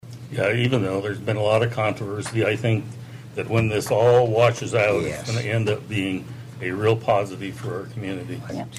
Councilman Gerald Brink admitted dissolving the Park and Recreation Board caused some controversy, but he is optimistic about the future of this commission.